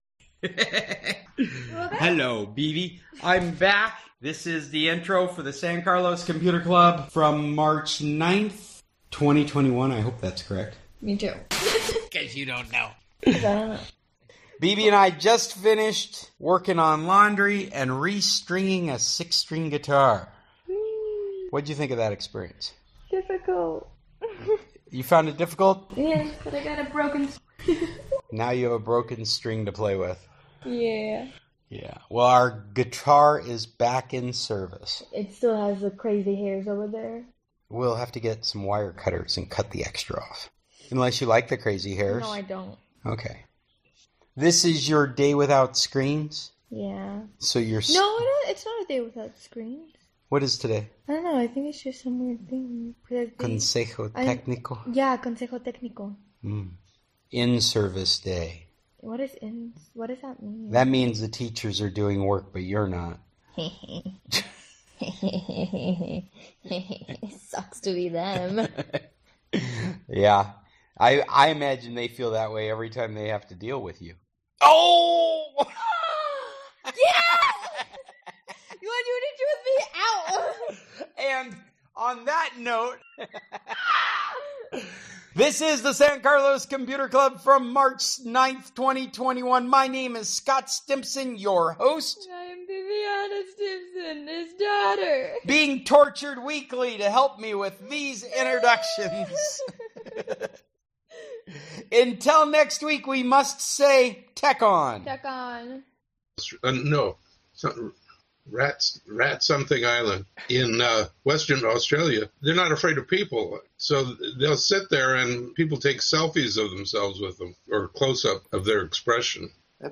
We Welcome All Tech Enthusiasts Today's meeting covered the following topics: Rottnest Island - Rottnest is well known for its population of quokkas, a small native marsupial found in very few other locations.